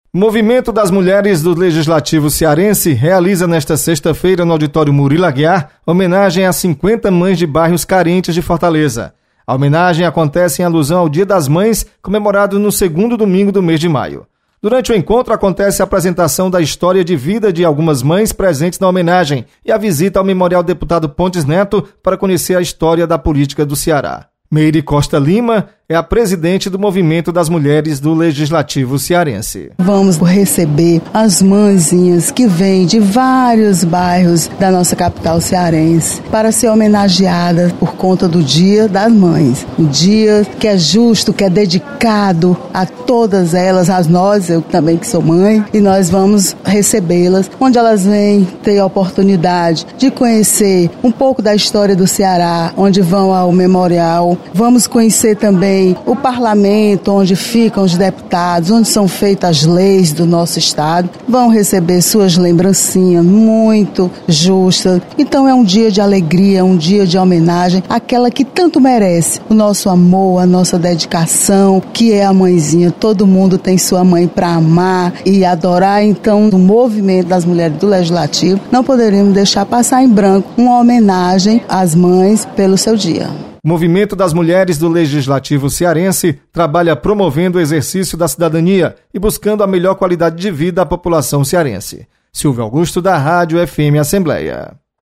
Você está aqui: Início Comunicação Rádio FM Assembleia Notícias Mãe